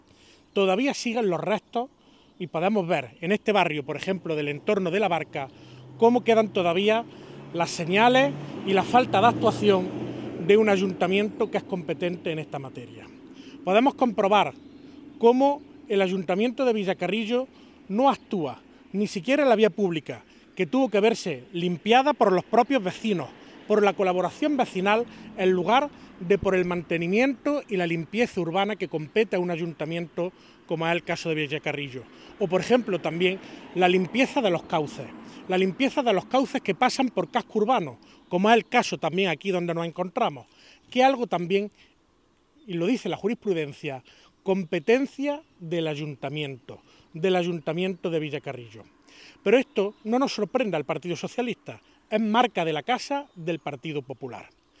El parlamentario hizo estas declaraciones en el transcurso de una visita a la zona afectada